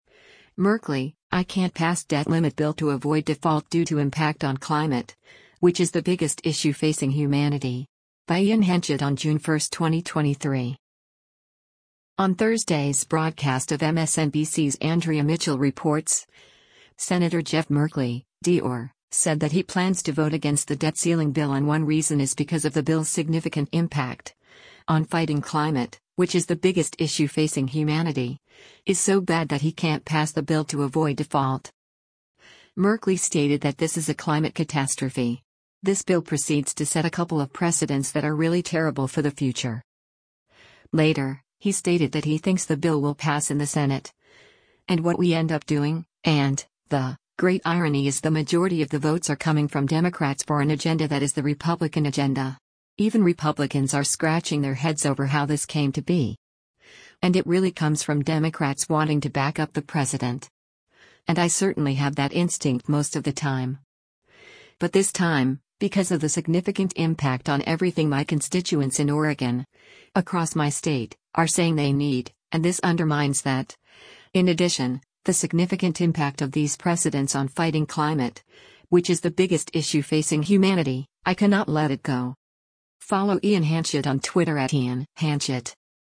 On Thursday’s broadcast of MSNBC’s “Andrea Mitchell Reports,” Sen. Jeff Merkley (D-OR) said that he plans to vote against the debt ceiling bill and one reason is because of the bill’s “significant impact…on fighting climate, which is the biggest issue facing humanity,” is so bad that he can’t pass the bill to avoid default.